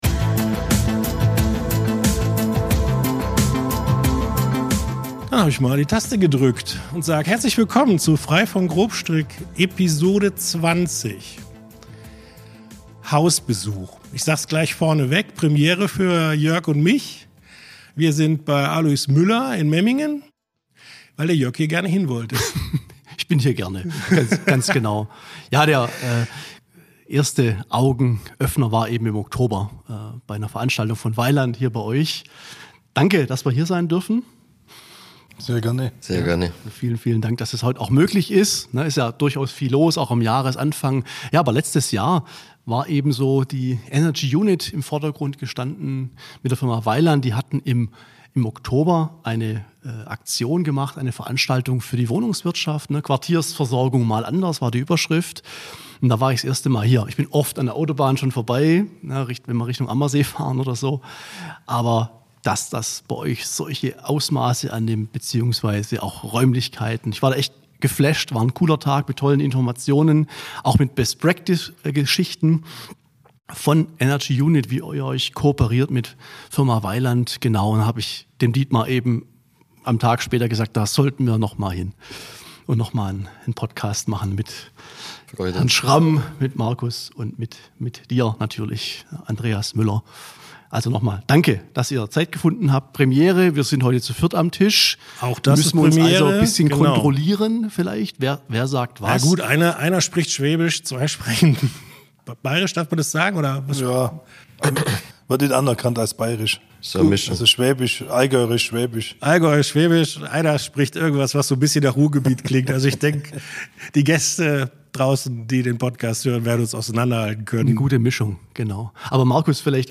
Premiere für das neue Podcast-Format „Hausbesuch“!
Locker bis launig, meinungsstark und informativ, das ist Frei von Grobstrick, der HeizungsJournal-Podcast.